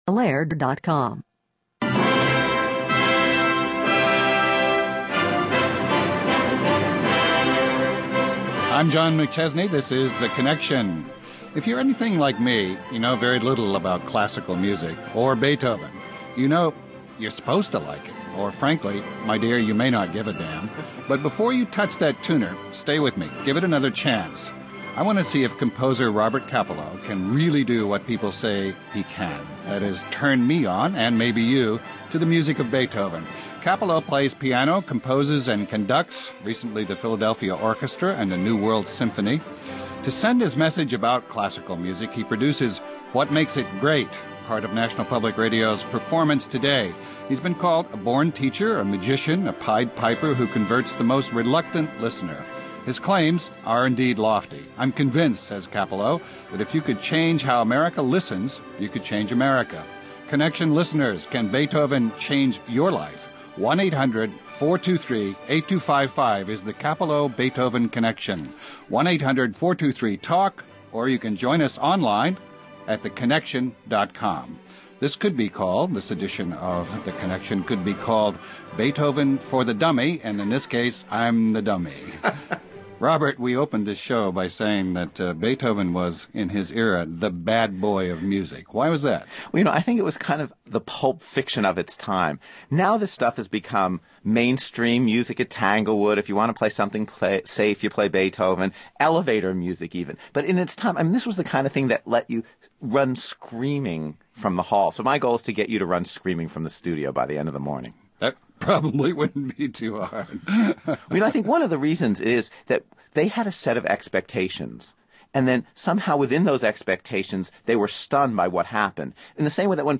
So Connection listeners, what’s YOUR opinion? What do you think about George Bush’s address Tuesday night?
(Hosted by Christopher Lydon)